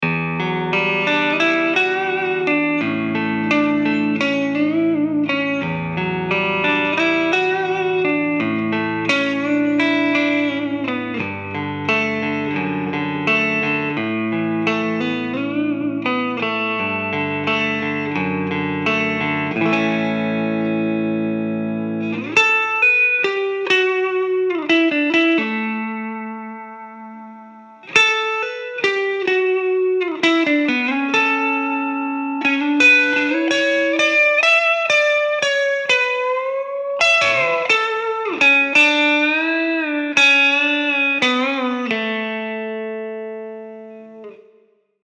Vintage output Telecaster rhythm tone but with alnico 2 rod magnets for a sweeter, smoother treble response.
APTR-1_CLEAN_SOLO_SM